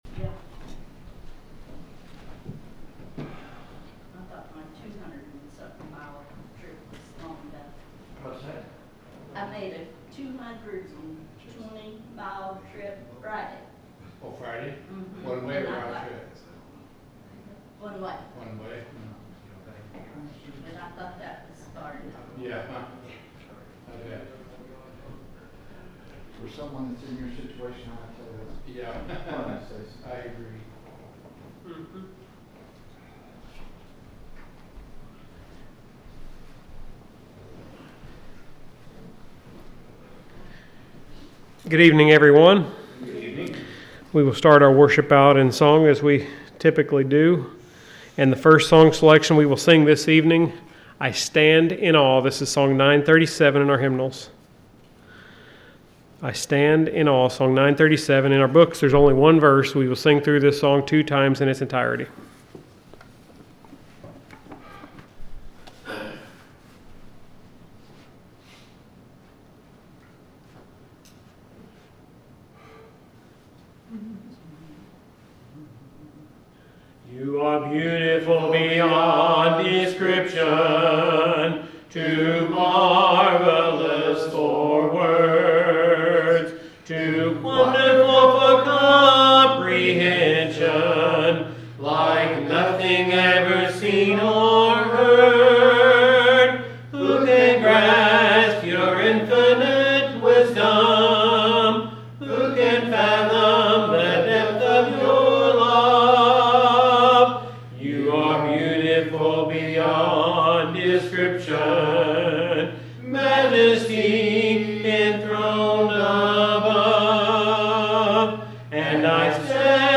The sermon is from our live stream on 7/27/2025